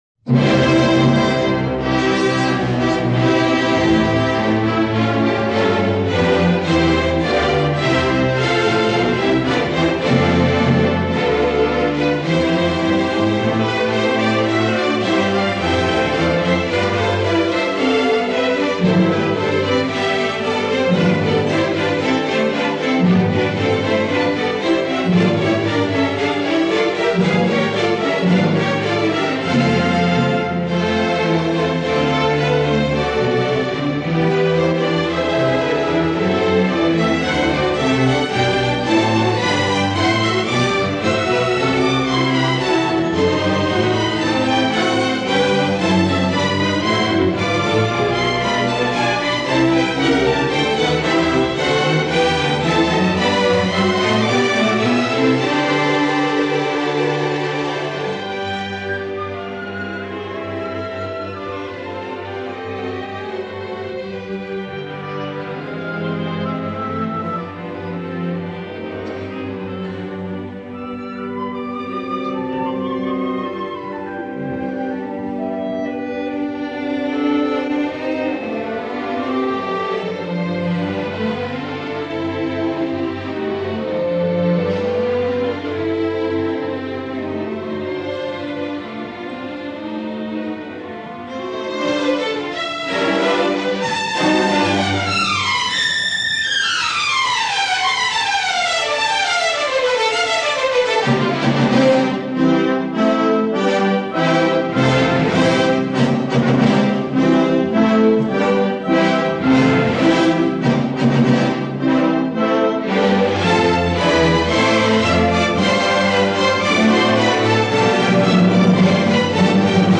音樂類型：古典音樂
1951年 拜魯特現場錄音